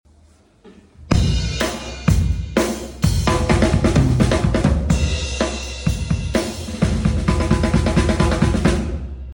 laying down the intro beat to some iconic tracks